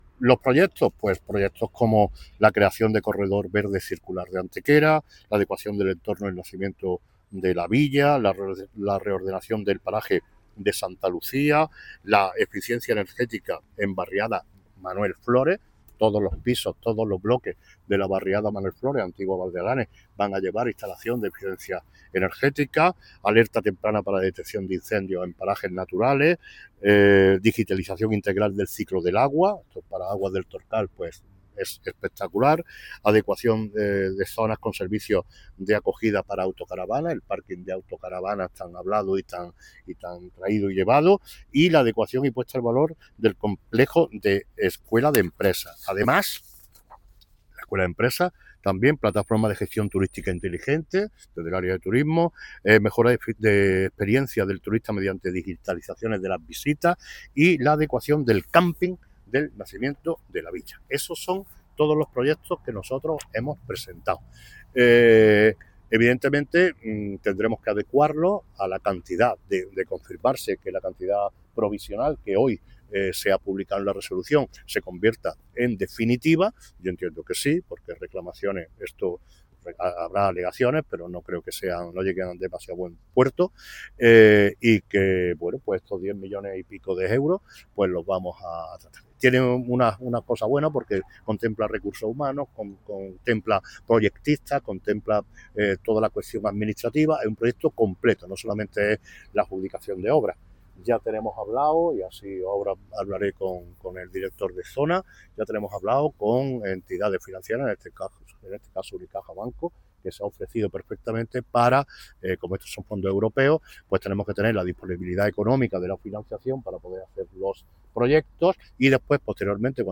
El alcalde de Antequera, Manolo Barón, ha dado a conocer hoy viernes 3 de octubre en rueda de prensa la resolución provisional por la que el Ayuntamiento de Antequera recibirá más de 10 millones de euros de fondos FEDER, en el marco del programa 2021-2027 para Planes de Actuación Integrados (PAI).
Cortes de voz